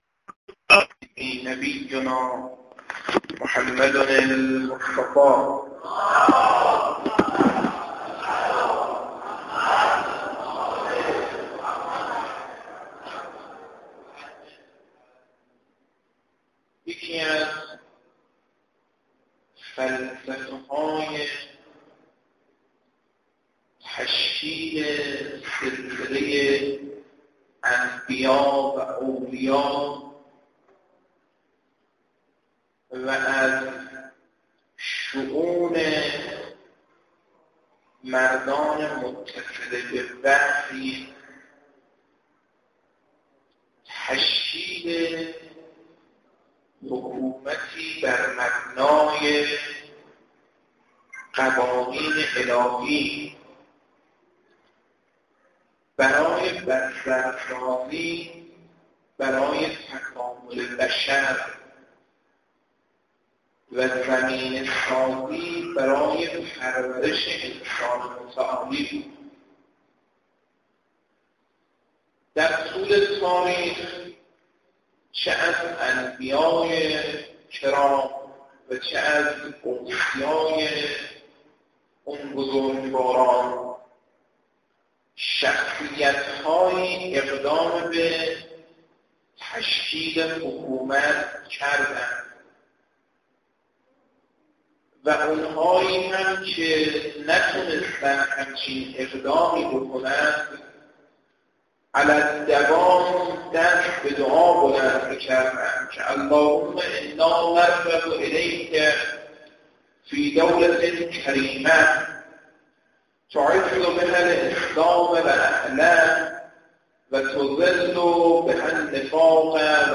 در ادامه فایل صوتی کامل این سخنرانی ارائه میشود : لينك دانلود گزارش خطا 0 پسندیدم لینک کوتاه کپی لینک اخبار ویژه وقتی ظریف تبدیل به فرصت بین المللی می‌شود!